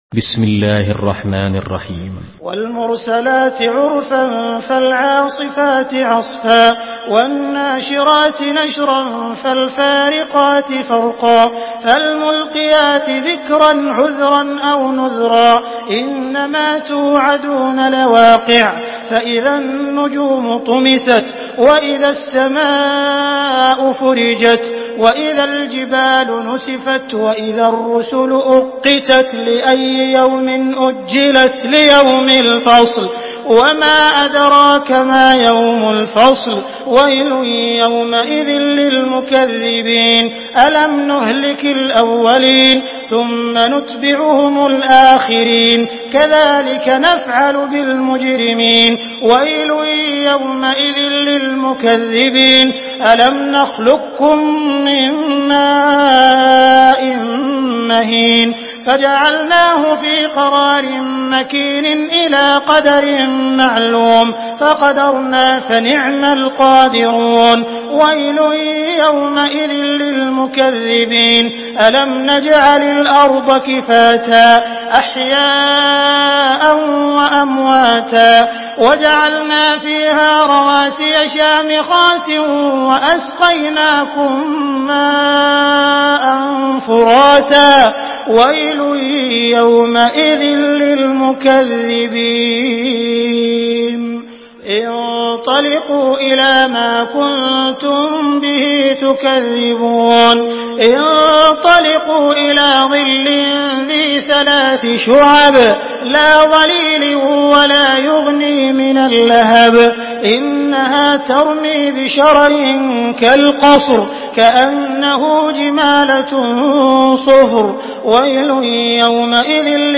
سورة المرسلات MP3 بصوت عبد الرحمن السديس برواية حفص
مرتل